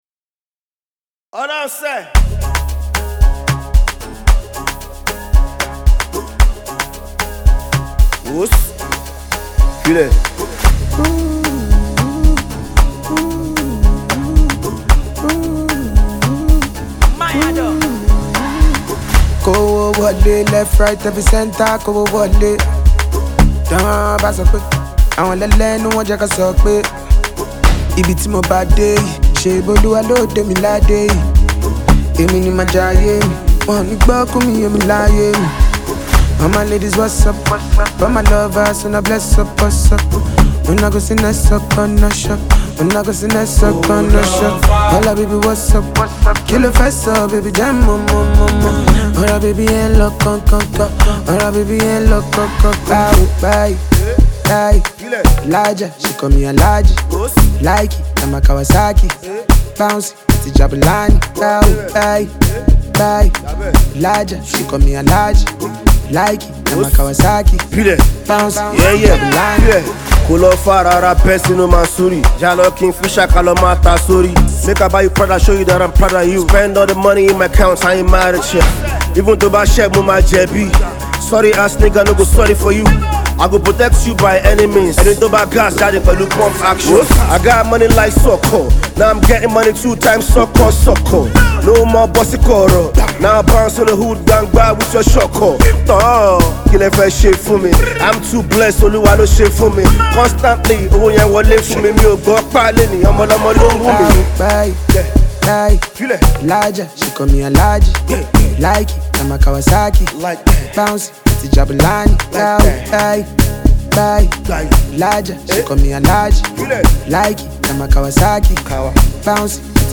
delivering sharp verses with confidence and grit.